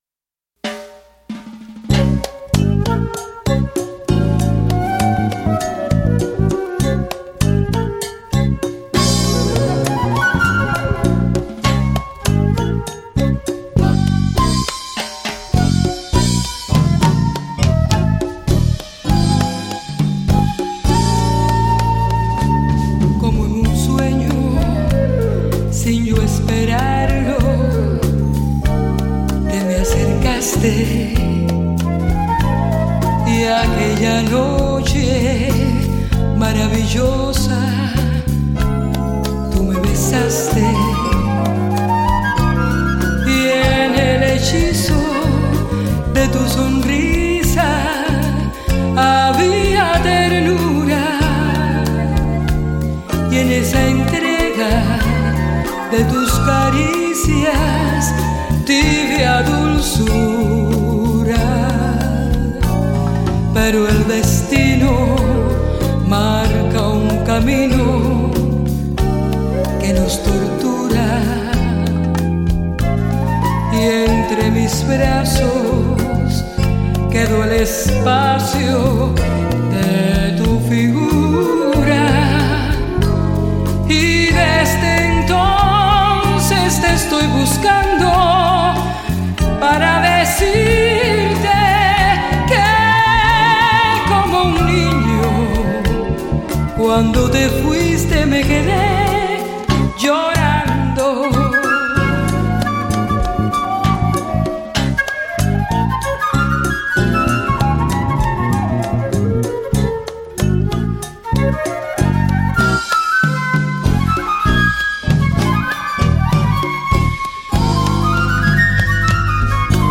Žánr: Jazz/Blues
najkrajsie kubanske bolera